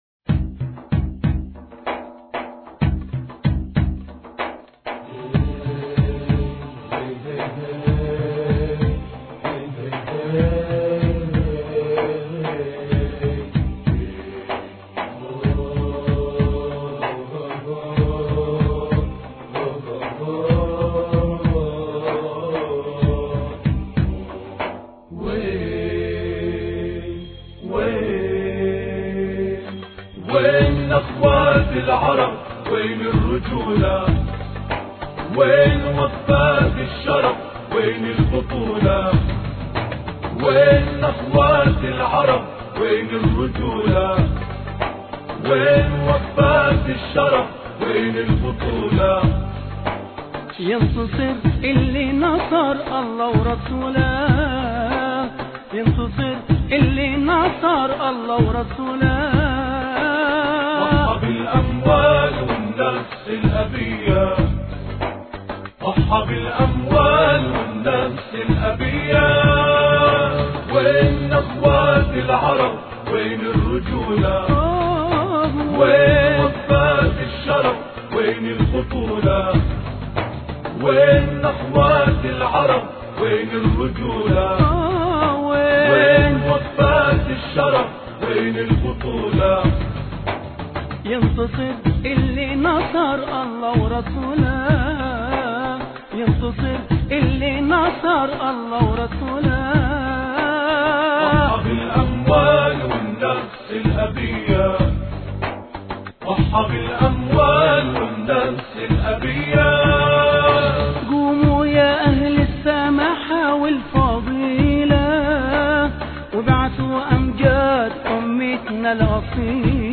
غزة الثلاثاء 27 يناير 2009 - 00:00 بتوقيت طهران تنزيل الحماسية شاركوا هذا الخبر مع أصدقائكم ذات صلة الاقصى شد الرحلة أيها السائل عني من أنا..